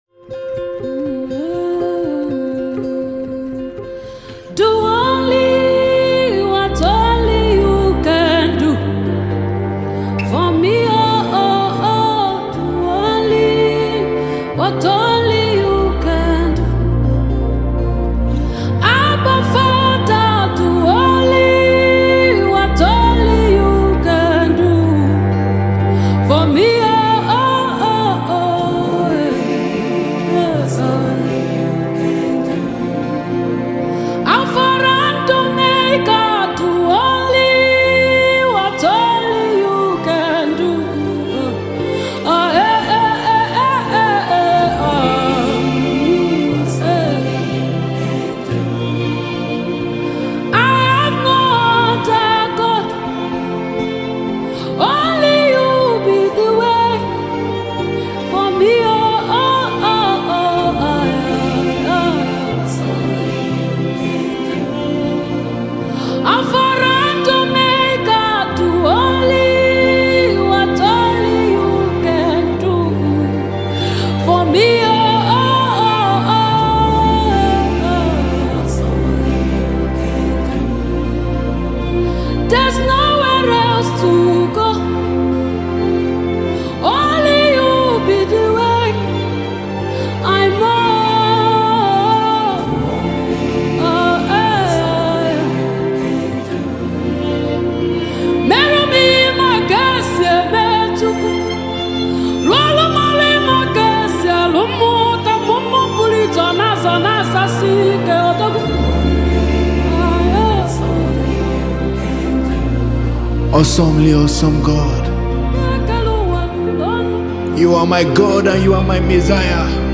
heartwarming song